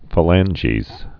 (fə-lănjēz, fā-)